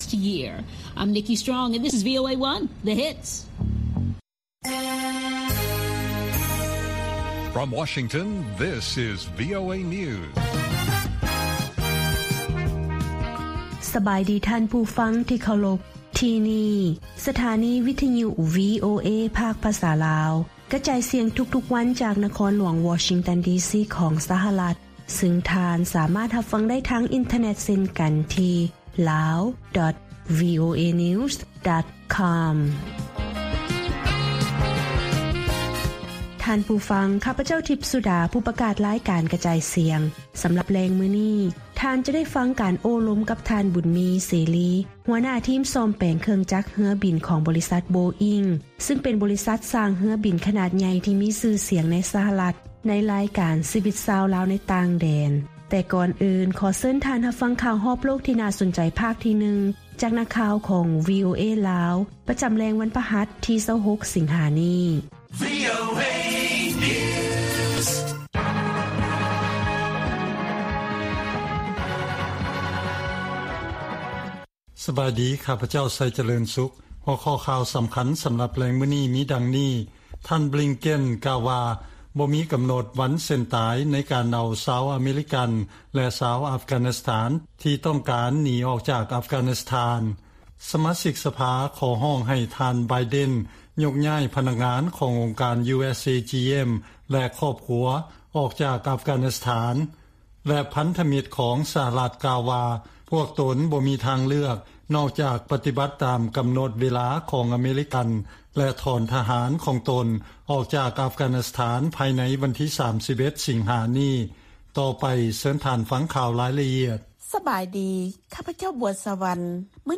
ວີໂອເອພາກພາສາລາວ ກະຈາຍສຽງທຸກໆວັນ. ຫົວຂໍ້ຂ່າວສໍາຄັນໃນມື້ນີ້ມີ: 1) ຄົນລາວຢູ່ ສຫລ ທີ່ເຄີຍເປັນໂຄວິດ ບອກວ່າ ຫລັງຈາກເປັນພະຍາດນີ້ແລ້ວ ຮ່າງກາຍບໍ່ຄືເກົ່າ ຈຶ່ງຢາກແນະນໍາໃຫ້ທຸກຄົນສັກຢາວັກຊີນ.